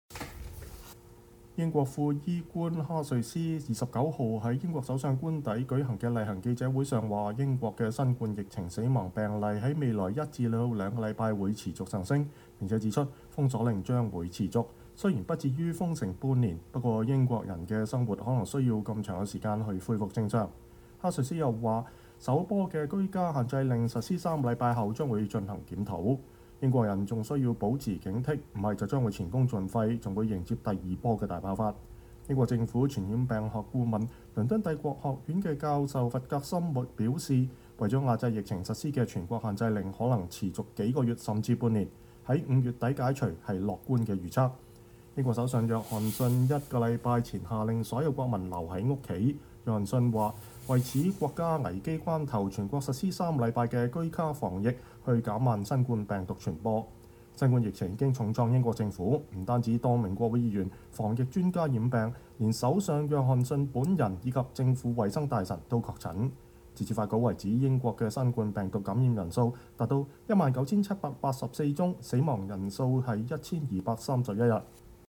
英國副醫療官哈瑞斯(Jenny Harries)29日在首相官邸的例行記者會上表示，英國的新冠疫情死亡病例在未來1到2週會持續上升，並指出封鎖令將會持續，雖然不至於封城半年，不過民眾的生活可能需要這麼長的時間來恢復正常。